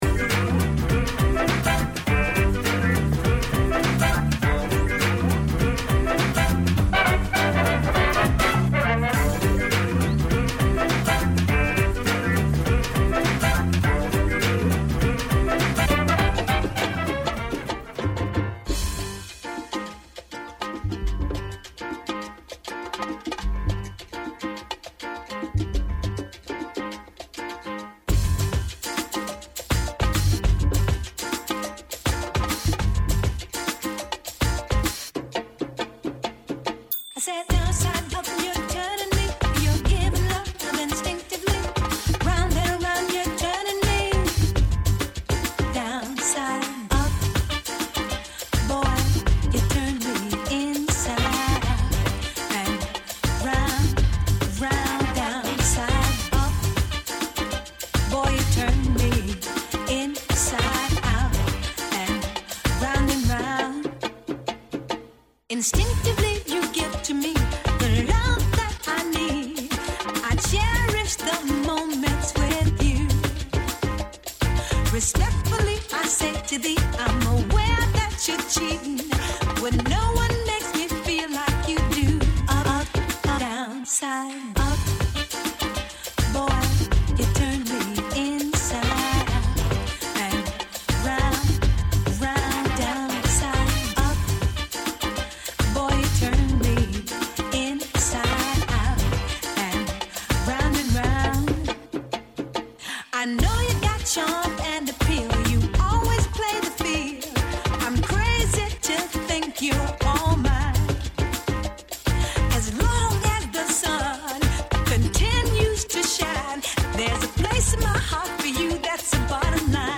Electro Swing, Jazzy House, Funky Beats DJ for Hire